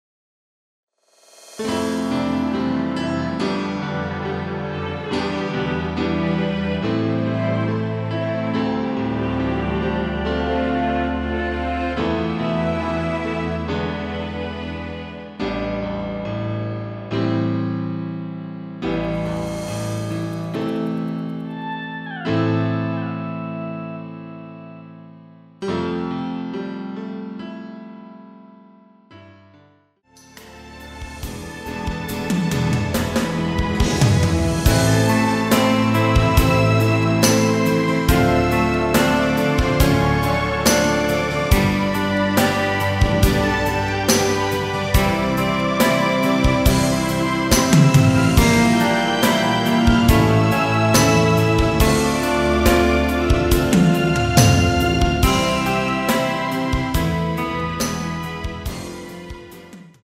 *부담없이즐기는 심플한 MR
Am
◈ 곡명 옆 (-1)은 반음 내림, (+1)은 반음 올림 입니다.
앞부분30초, 뒷부분30초씩 편집해서 올려 드리고 있습니다.
중간에 음이 끈어지고 다시 나오는 이유는